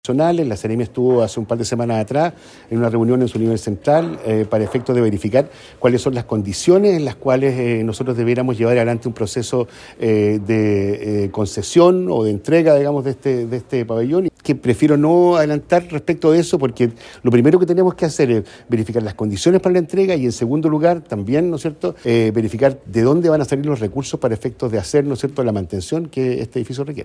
En el corto plazo, el tema no se zanjará, pero, el delegado presidencial, Eduardo Abdala, dijo que están barajando opciones para entregar una concesión, indicando que por el momento se desconoce quién se hará cargo de financiar su mantención.